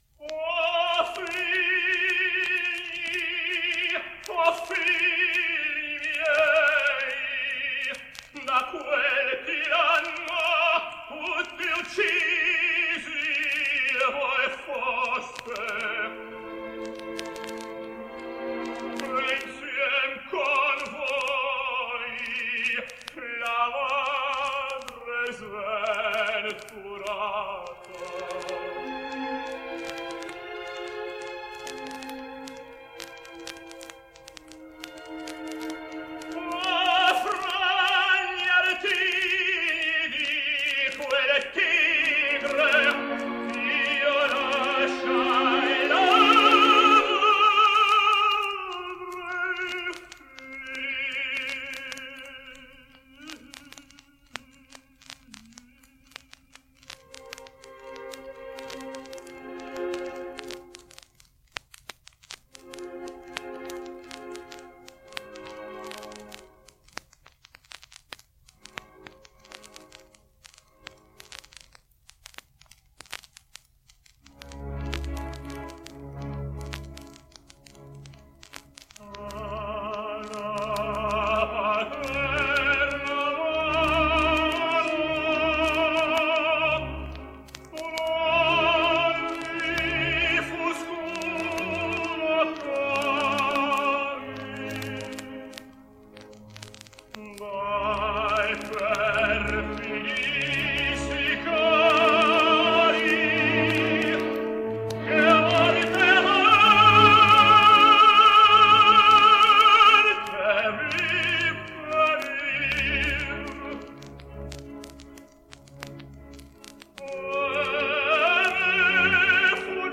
American Tenor
Still in his 30’s, his voice is beginning to darken, and he is contemplating some of the heavier French and Italian spinto roles.